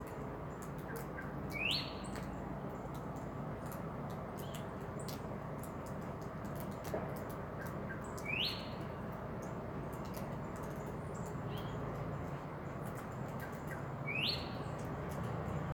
Variable Oriole (Icterus pyrrhopterus)
Country: Brazil
Location or protected area: Gramado
Condition: Wild
Certainty: Observed, Recorded vocal